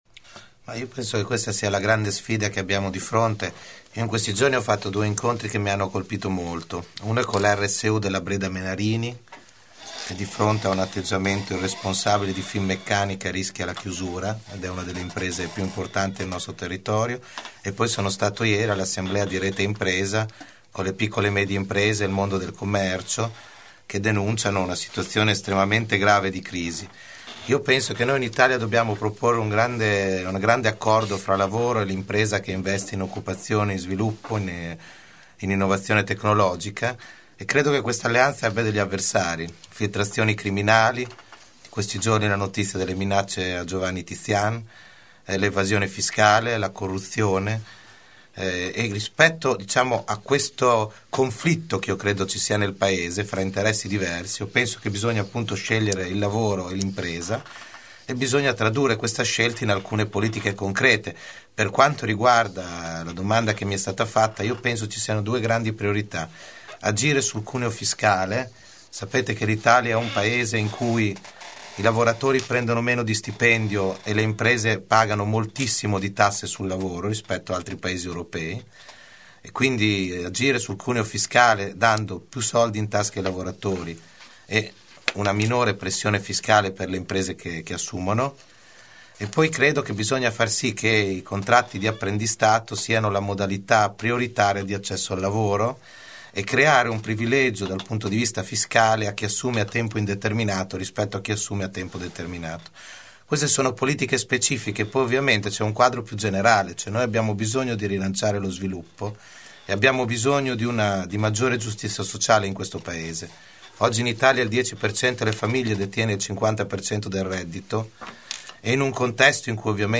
29 gen. – Disponibilità ad una revisione del Fiscal Compact e a discutere di salario minimo garantito sono arrivate da Andrea De Maria, candidato alla Camera per il Partito Democratico, ospite questa mattina di AngoloB, nella prima tavola rotonda tra i candidati emiliano romagnoli alle prossime politiche.